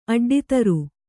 ♪ aḍḍitaru